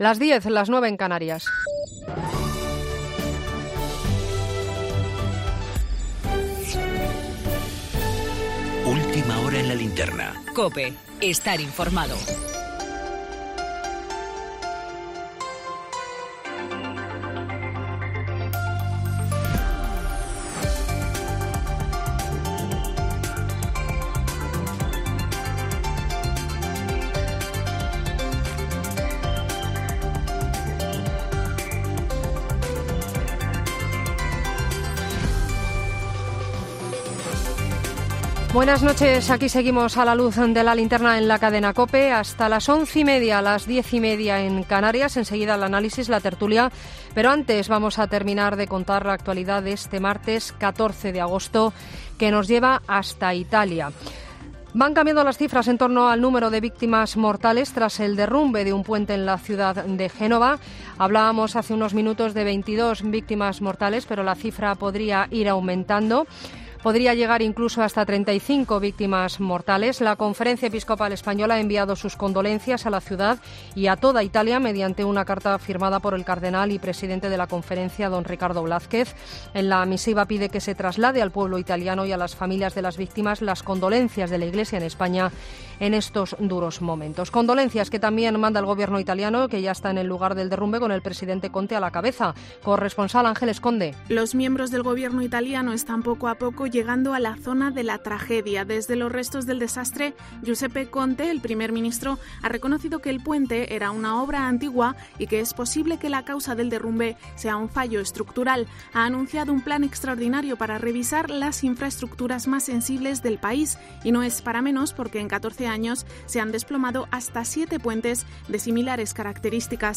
Tertulia I en ‘La Linterna’, martes 14 de agosto de 2018
Redacción digital Madrid - Publicado el 14 ago 2018, 22:00 - Actualizado 15 mar 2023, 13:34 3 min lectura Descargar Facebook Twitter Whatsapp Telegram Enviar por email Copiar enlace Escucha ahora ‘Tertulia I’, emitido el martes 14 de agosto, en LA LINTERNA .